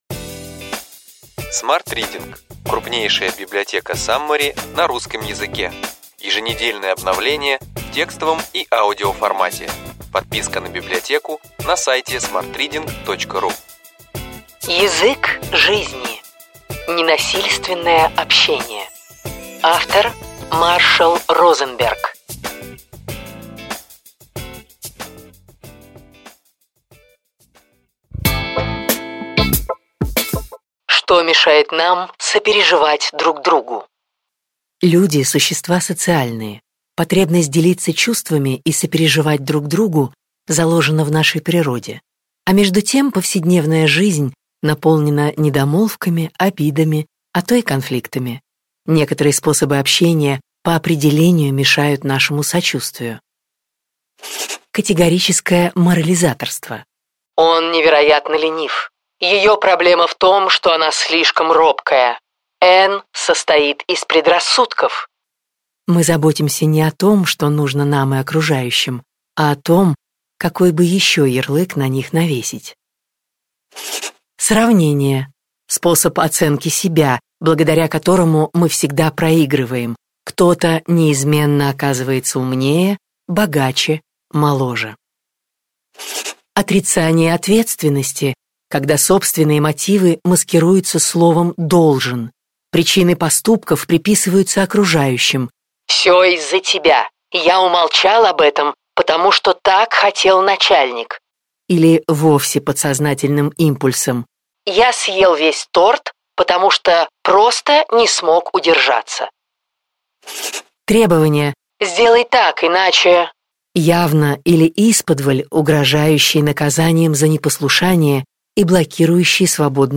Аудиокнига Ключевые идеи книги: Язык жизни. Ненасильственное общение.